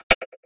Perc 14.wav